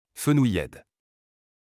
Fenouillèdes (French pronunciation: [fənujɛd]